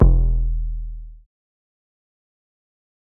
808 (Wings).wav